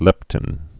(lĕptĭn)